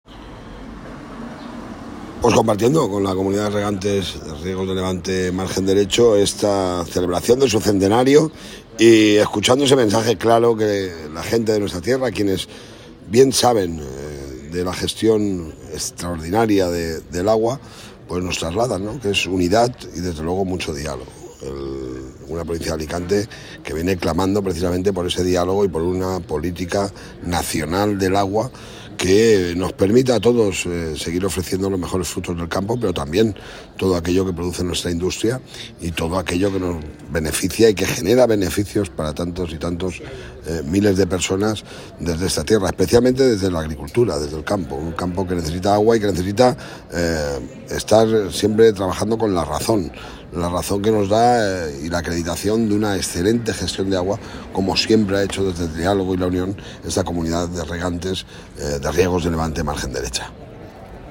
El presidente de la Diputacion de Alicante participa en el acto conmemorativo del centenario de esta entidad celebrado hoy en Rojales
Audio-Toni-Perez-Rojales-CR.m4a